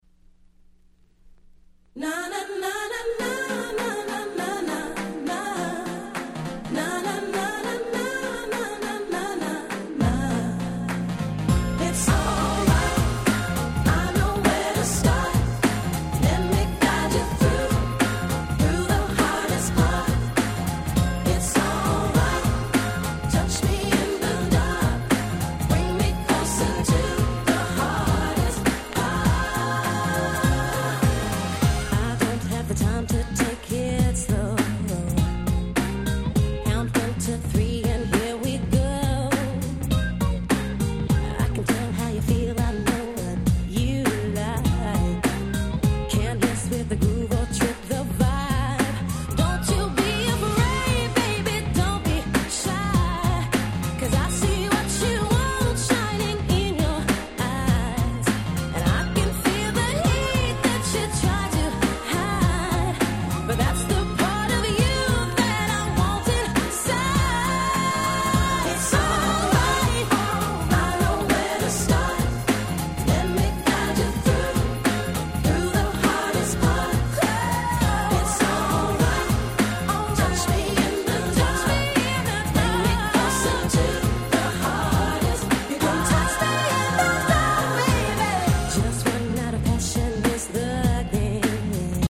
96' Nice R&B LP !!